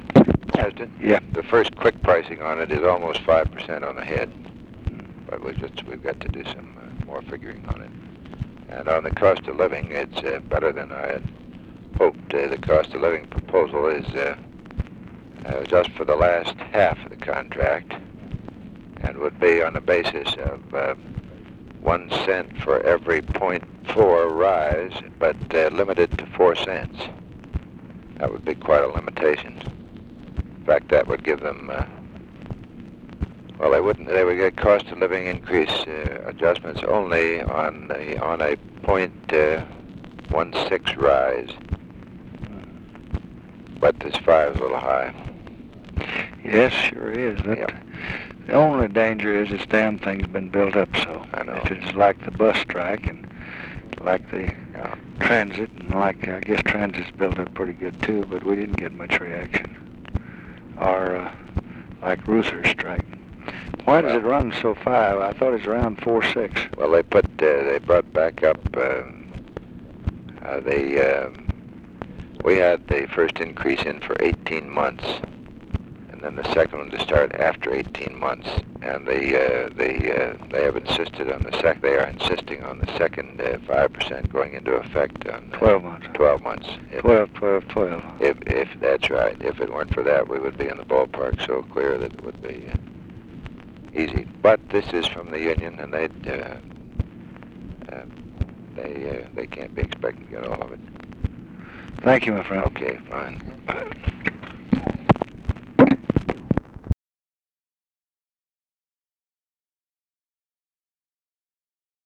Conversation with WILLARD WIRTZ, August 11, 1966
Secret White House Tapes